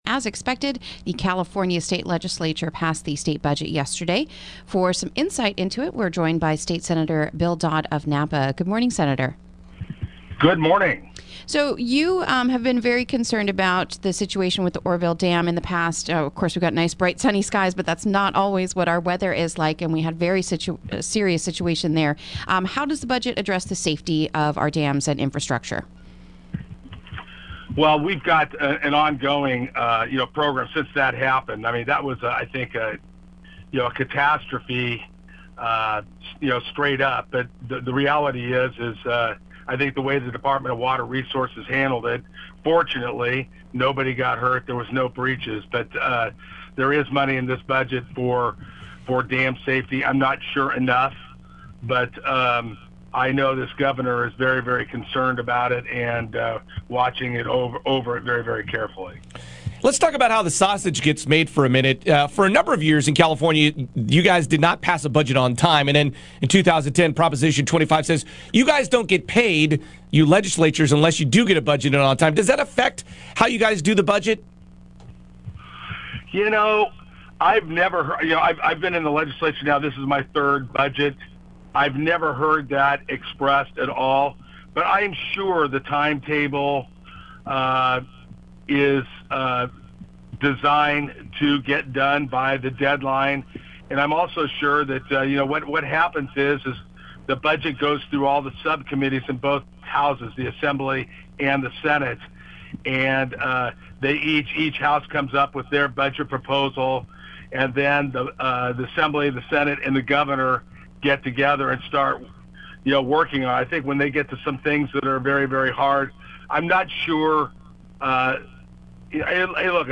Interview: $125 Million State Budget Passes
State Senator Bill Dodd, joins us to talk about the $125M state budget plan.